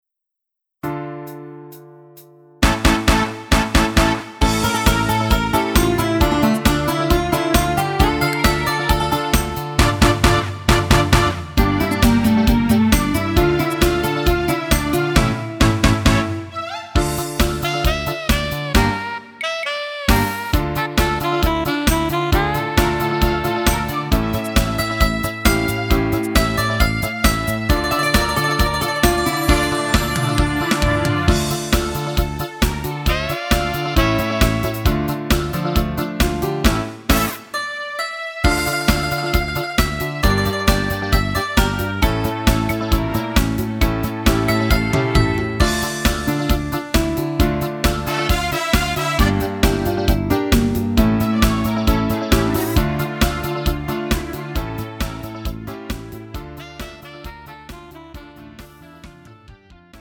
음정 원키 3:07
장르 가요 구분 Lite MR
Lite MR은 저렴한 가격에 간단한 연습이나 취미용으로 활용할 수 있는 가벼운 반주입니다.